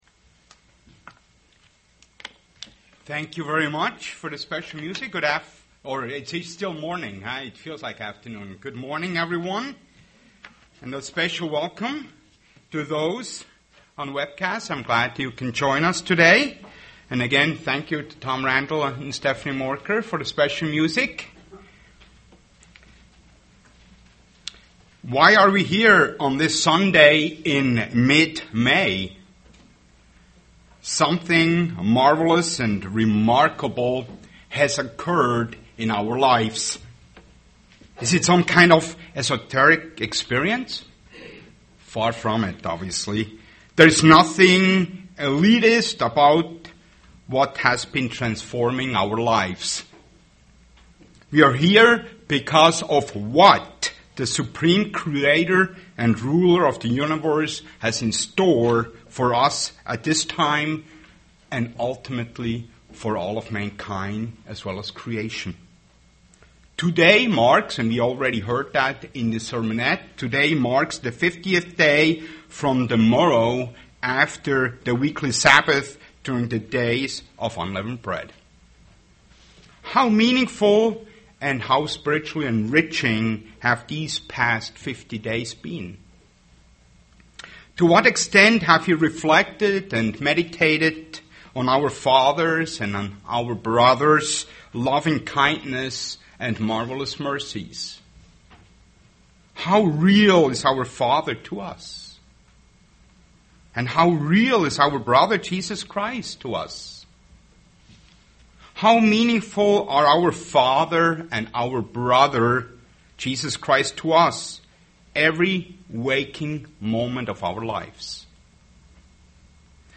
Given in Twin Cities, MN
UCG Sermon Pentecost Studying the bible?